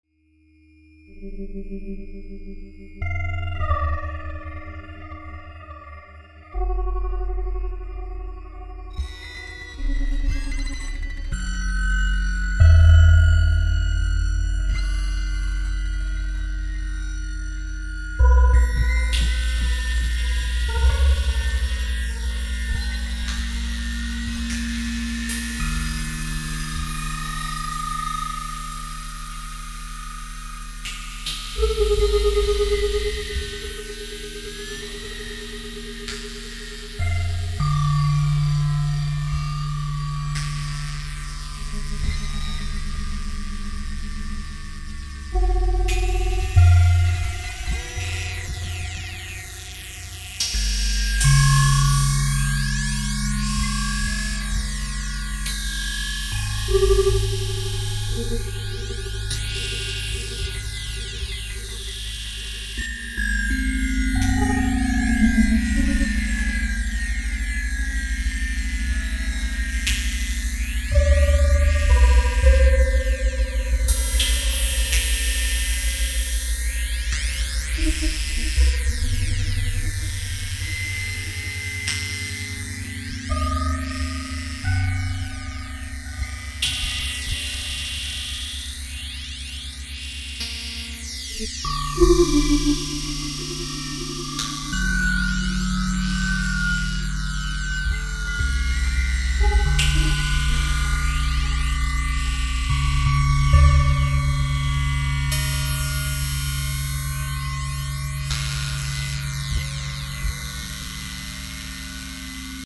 electronic music